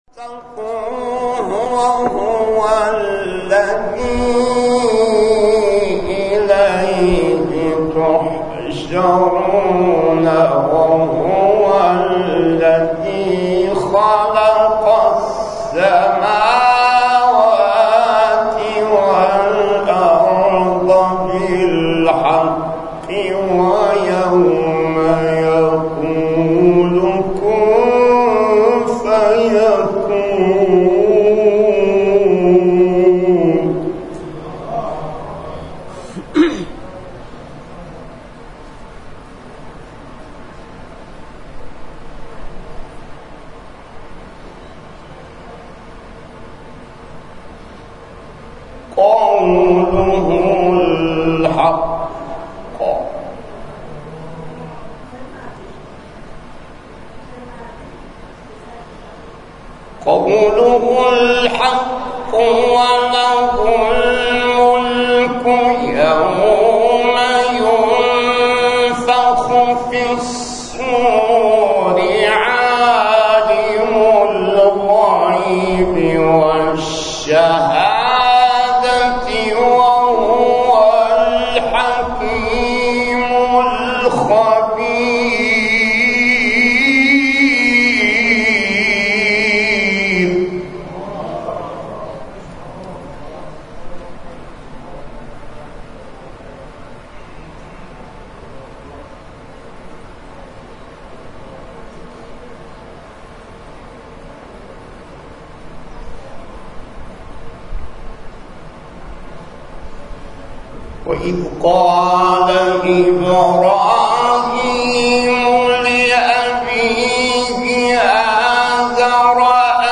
در مسجد امام حسین(ع) برگزار شد.
تلاوت